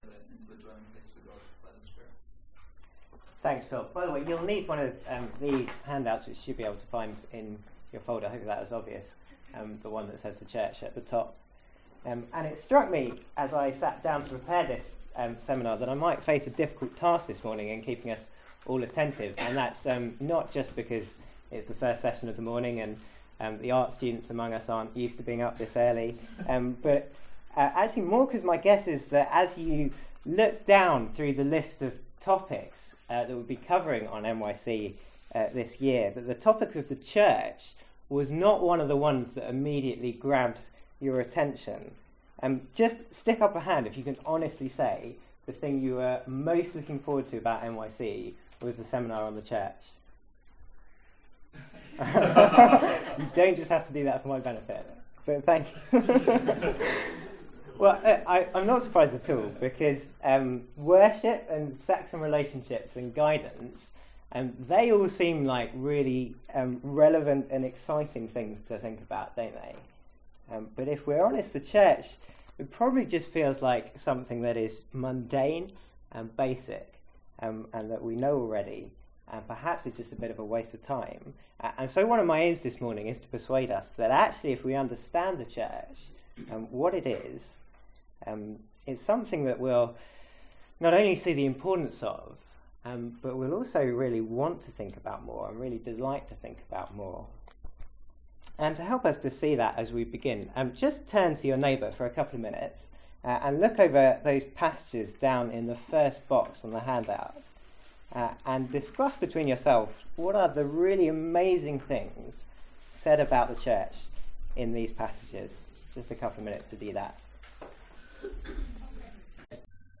Seminar from MYC14.